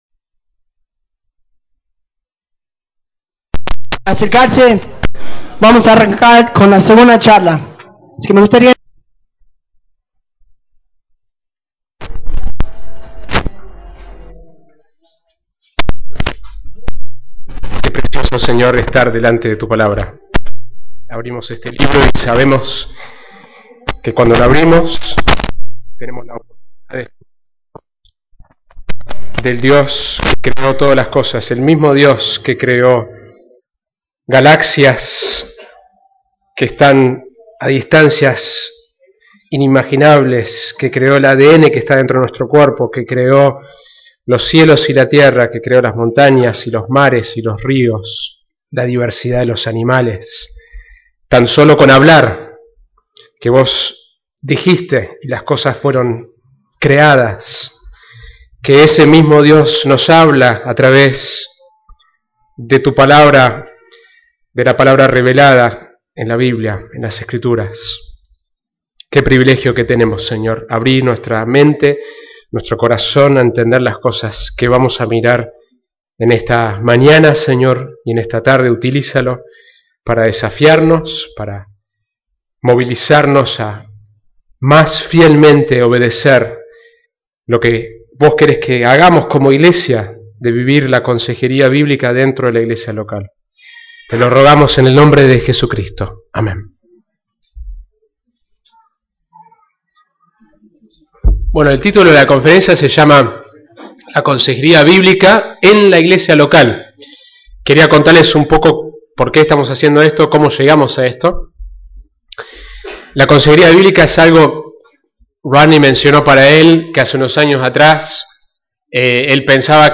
Serie: Conferencia Consejería Bíblica 2014